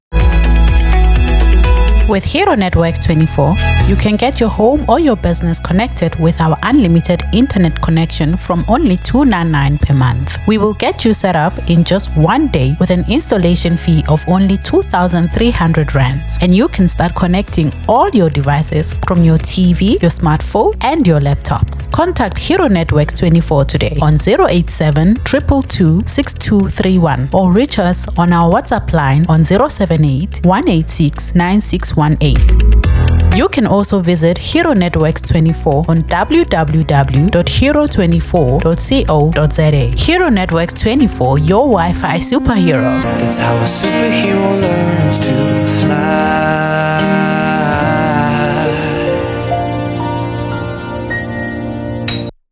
Here is a selection of just some of the adverts that have aired on UB FM LIVE, done for one of our main sponsor Hero Networks24 Inc..